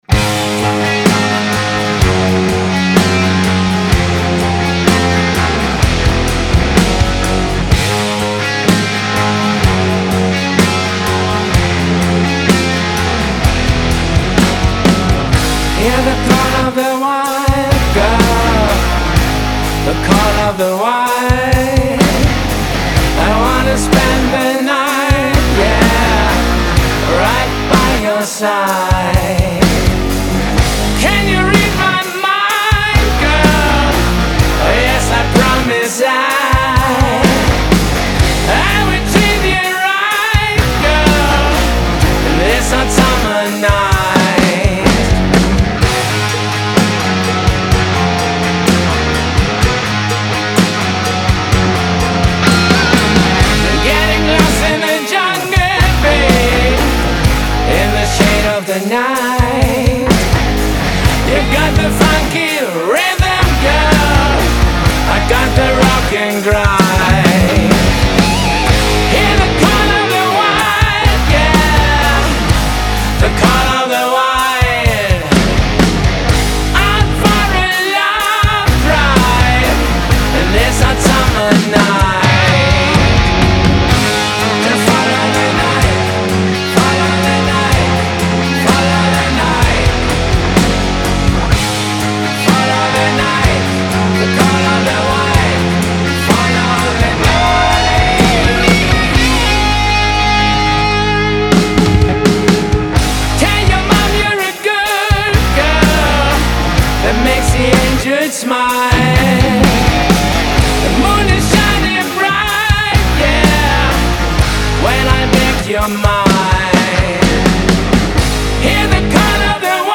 Genre : Hard Rock